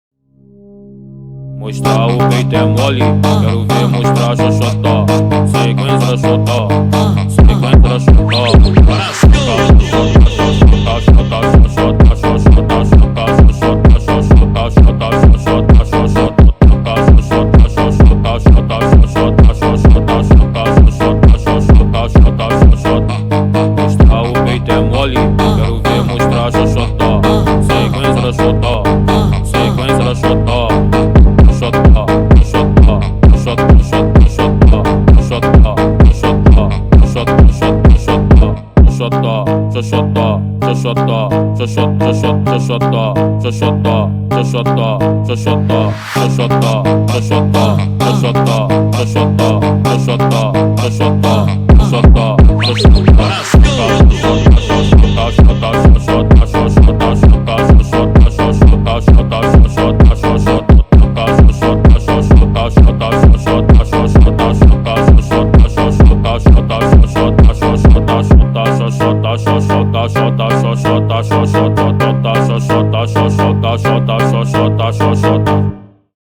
- (Brazilian phonk)